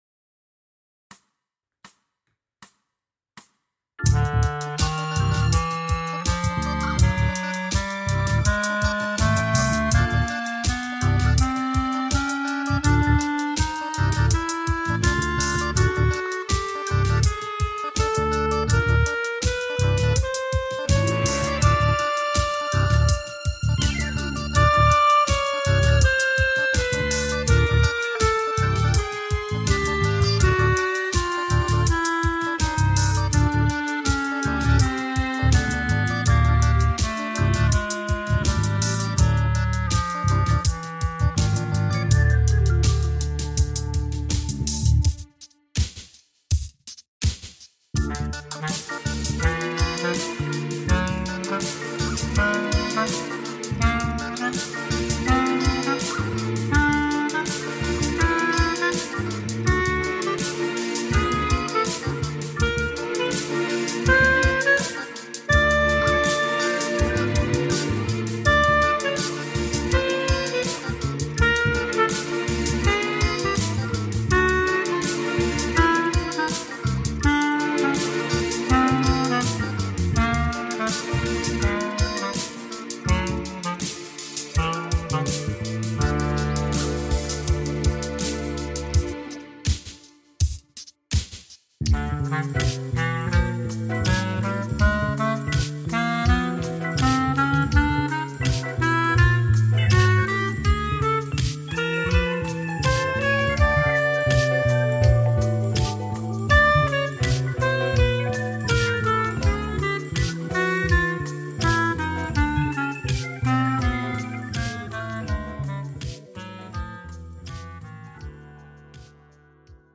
for clarinet